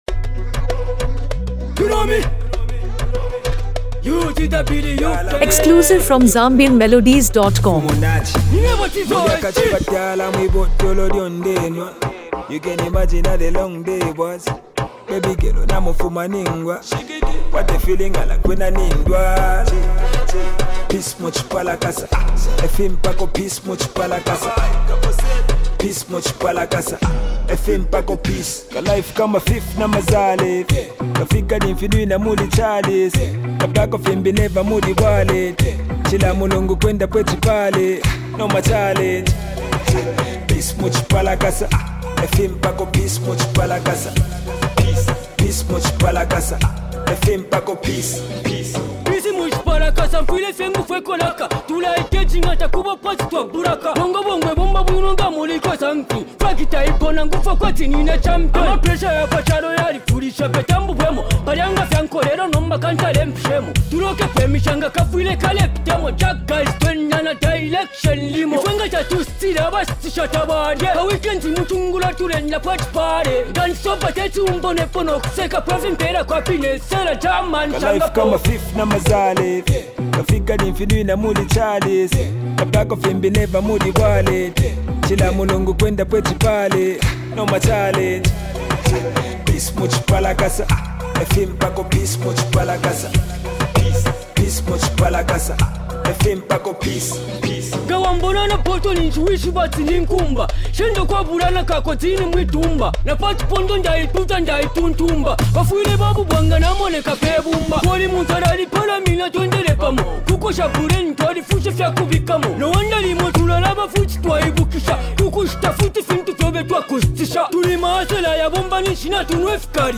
Zambian hip-hop collaborations